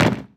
foot1.wav